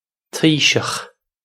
Taoiseach Tee-shukh
This is an approximate phonetic pronunciation of the phrase.